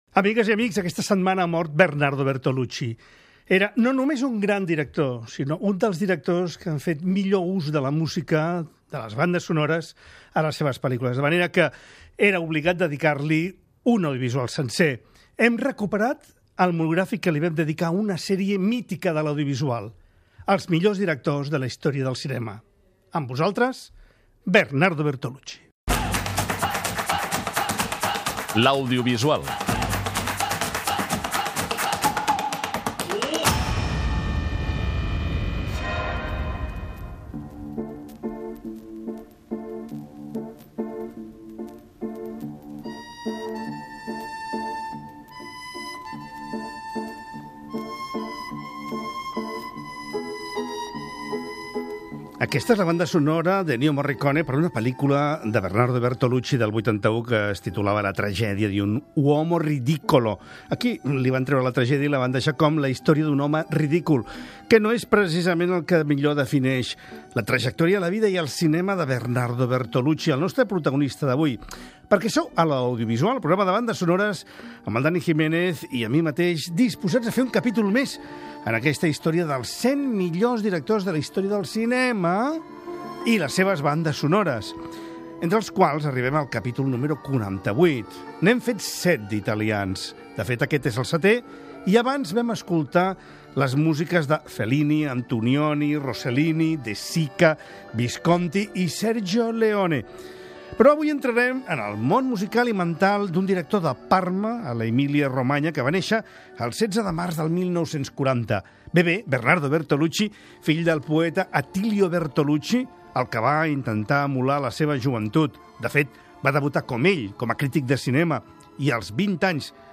Un programa especial en qu� podrem sentir algunes de les bandes sonores m�s destacades de les pel�l�cules que va fer al llarg de la seva carrera.